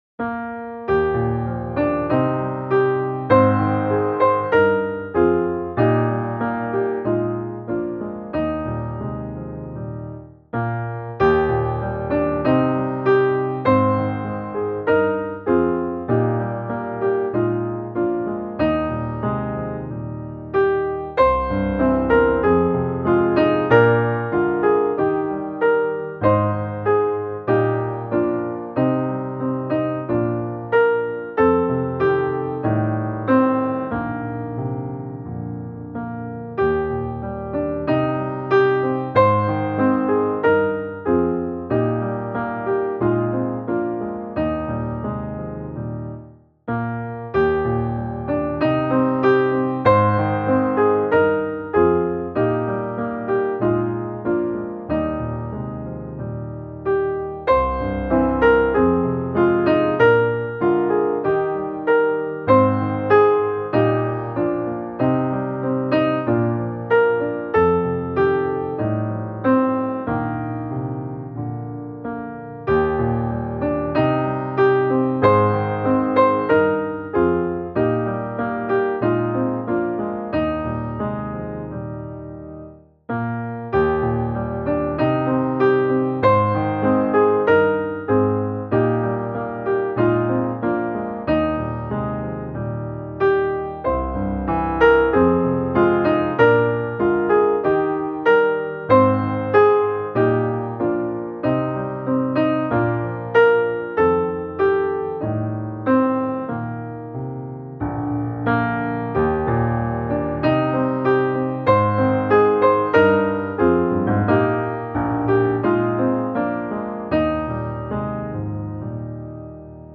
Ur djupet av mitt hjärta - musikbakgrund